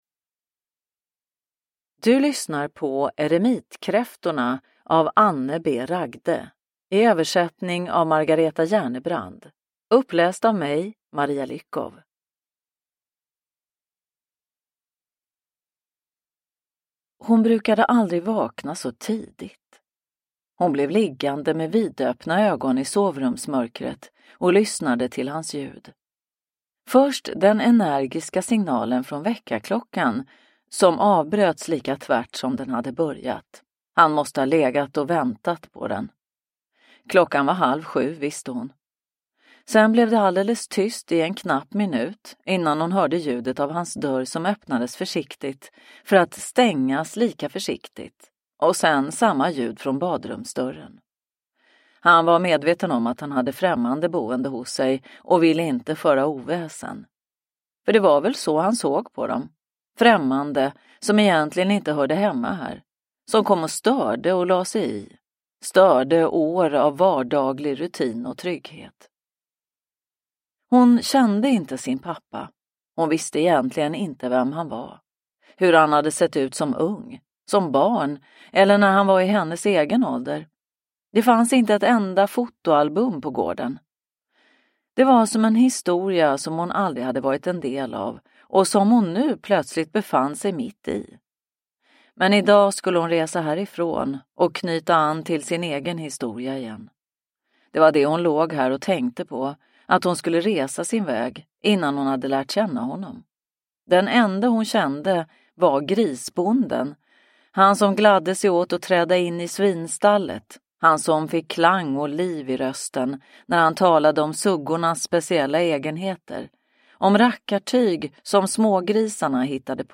Eremitkräftorna – Ljudbok – Laddas ner